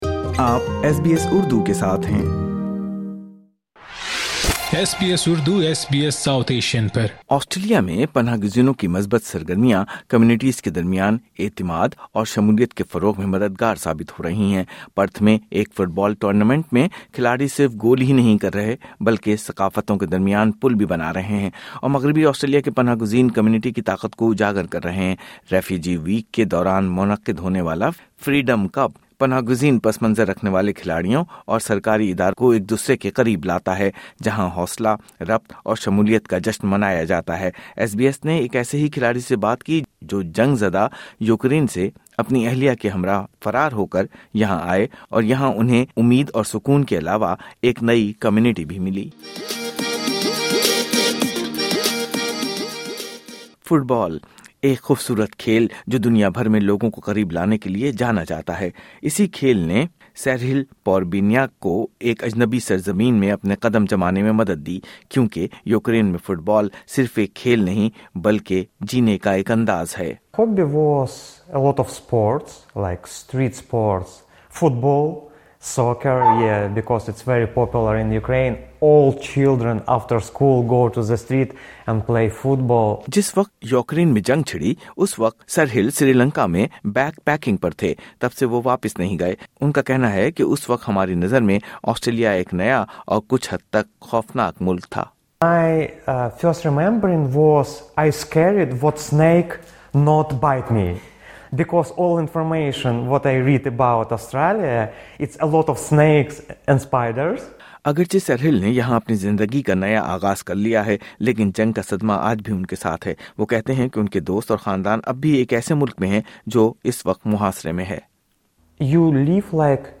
ایس بی ایس نے ایک ایسے کھلاڑی سے بات کی جو جنگ زدہ یوکرین سے اپنی بیوی کے ہمراہ فرار ہو کر یہاں آئے، اور یہاں اُنہیں اُمید، سکون کے علاوہ ایک نئی کمیونٹی بھی ملی۔